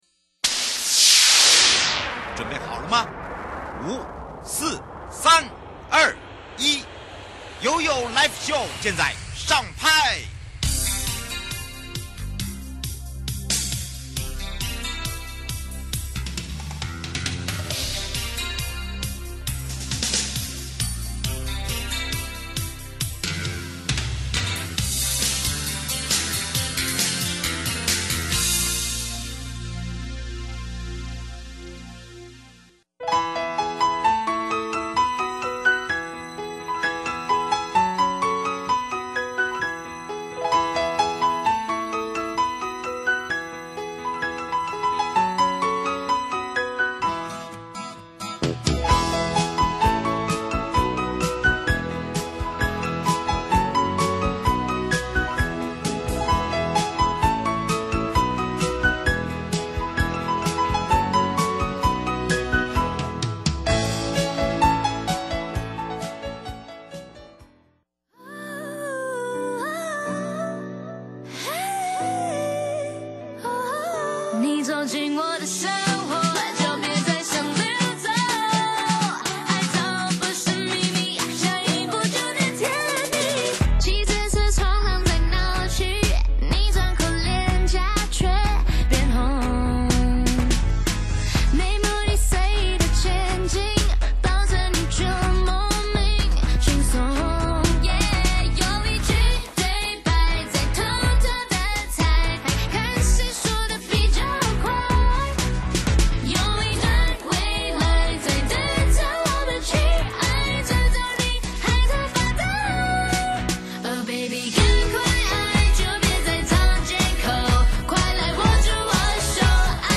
受訪者： 台北地檢 蕭奕弘檢察官 節目內容： 什麼是國民法官?什麼案件,會讓國民法官審理?誰可以當國民法官?國民法官怎麼產生?我可以拒絕當國民法官嗎 ?國民法官有報酬嗎 ?可以請公假嗎 ?